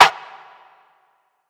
Snares
mamacita (snare)(1).wav